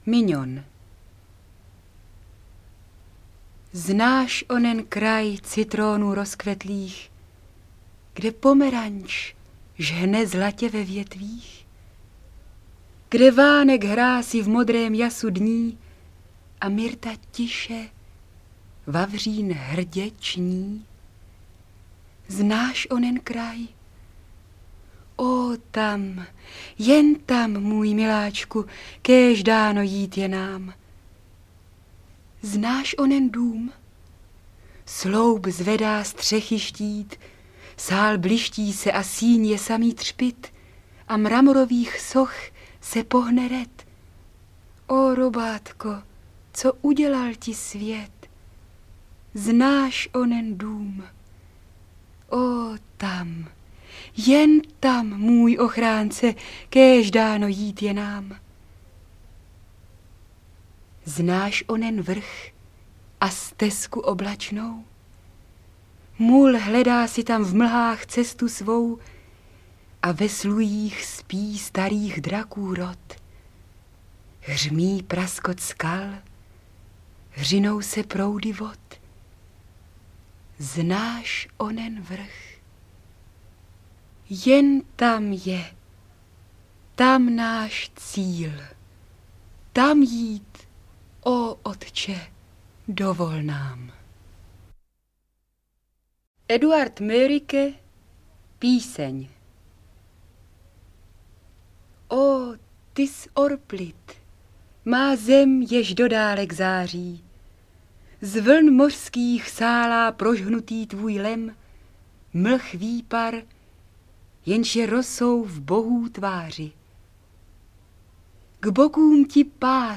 Recituje Irena Kačírková
beletrie / poezie